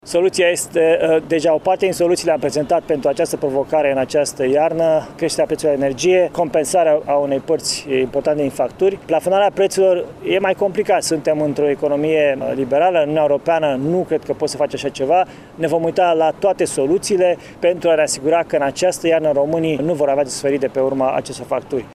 În conferinţa de presă care a urmat întâlnirii cu liberalii ieşeni, Florin Cîţu s-a referit, între altele, la creşterile de preţuri constatate în ultima perioadă.